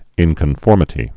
(ĭnkən-fôrmĭ-tē)